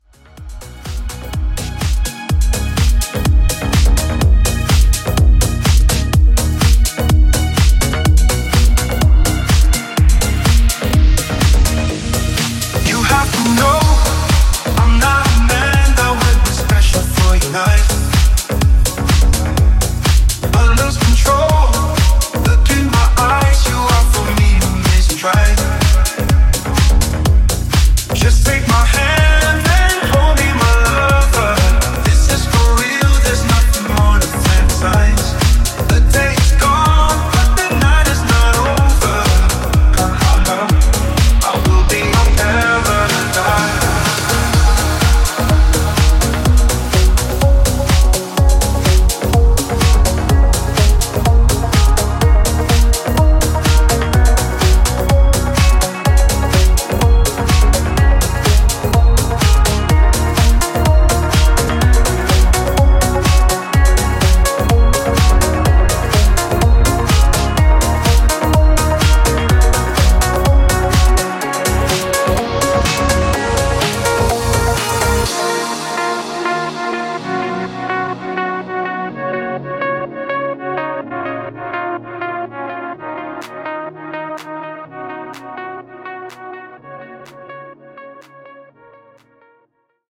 Club Extended Mix)Date Added